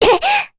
Rehartna-Vox_Damage_kr_02.wav